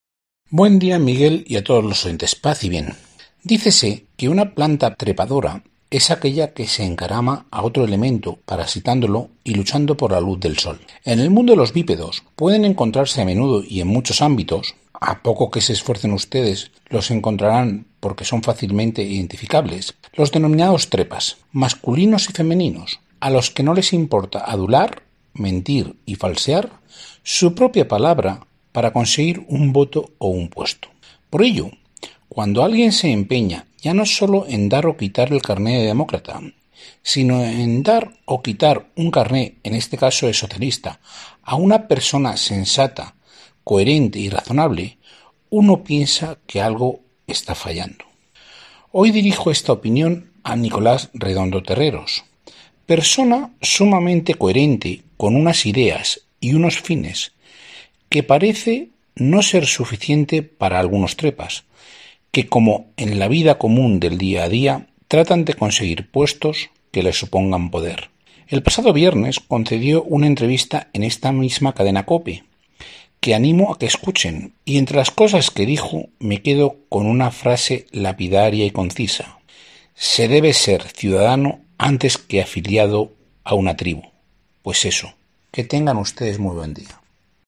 columna radiofónica